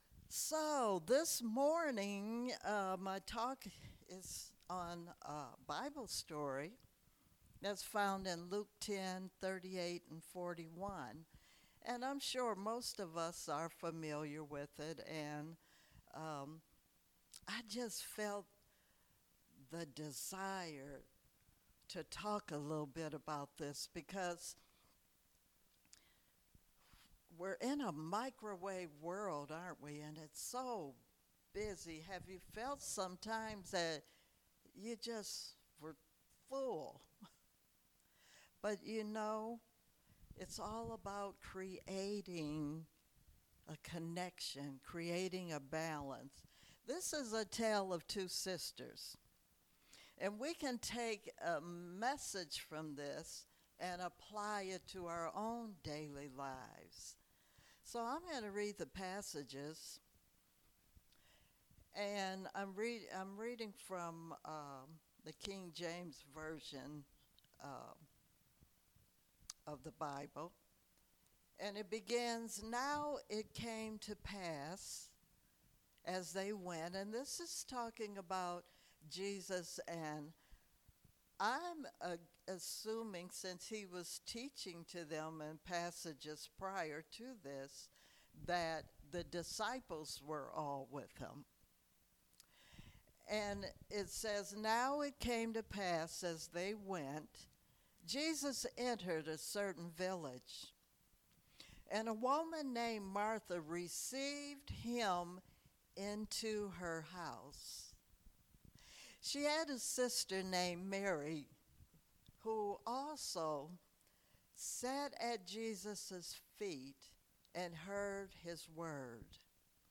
Series: Sermons 2021